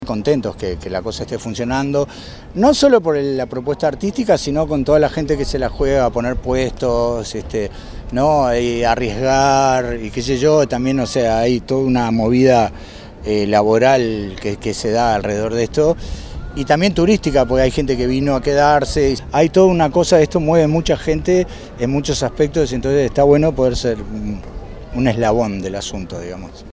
Fiesta de la Cerveza Artesanal en Parque del Plata